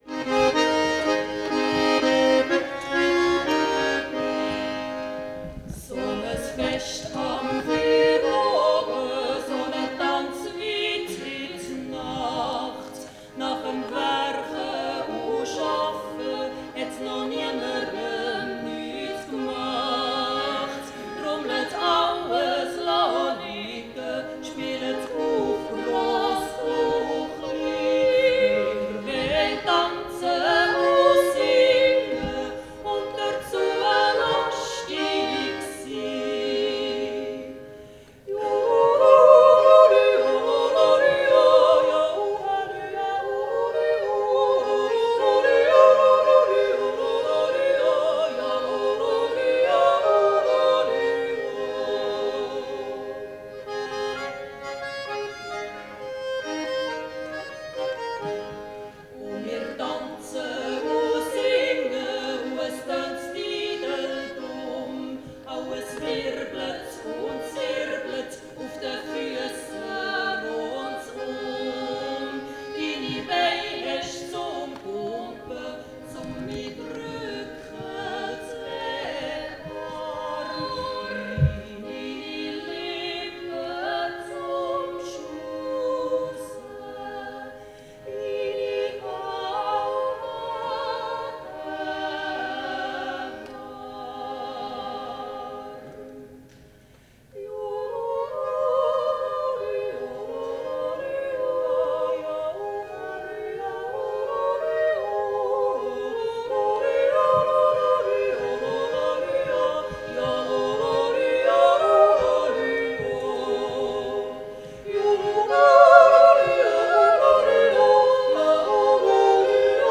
Ja, der Jodel selber ist schon etwas seltsam.
Jodel.wav